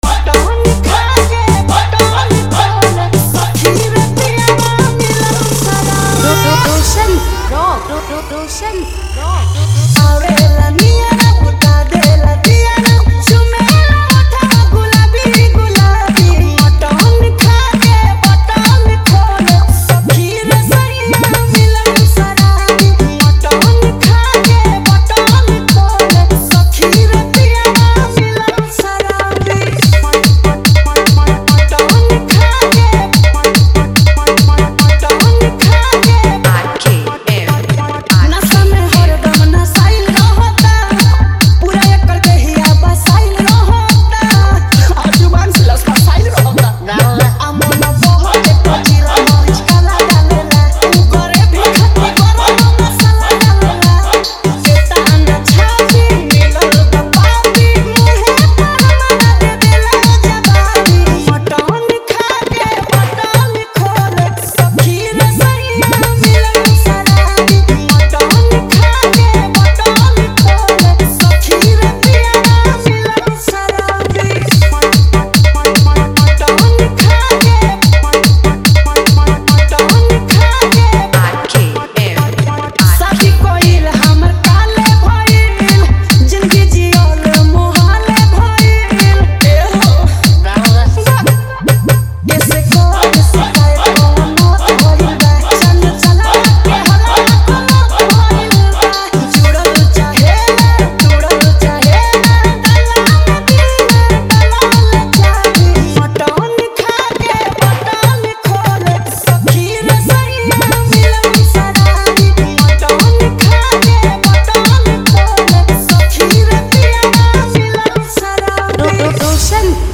Category : Bhojpuri Dj Remix Jhanjhan Bass